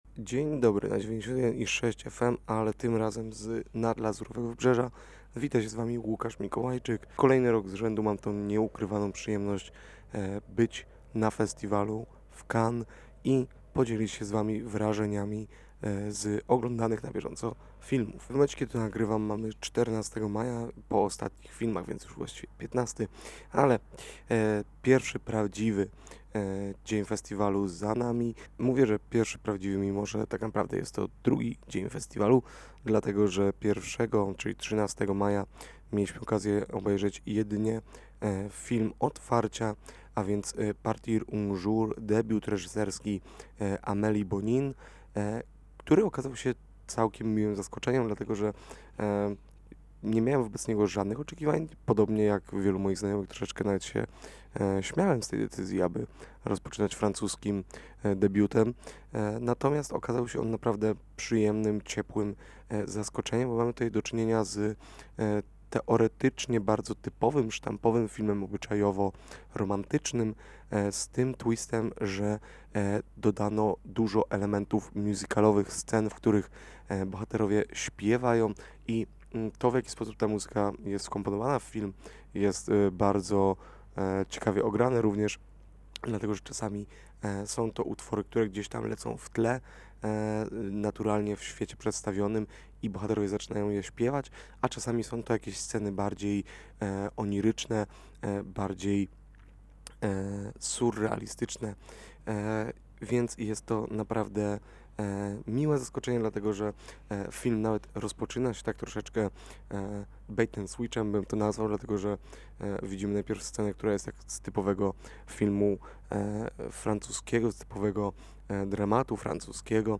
relacja-luz-dzien-1-i-2.mp3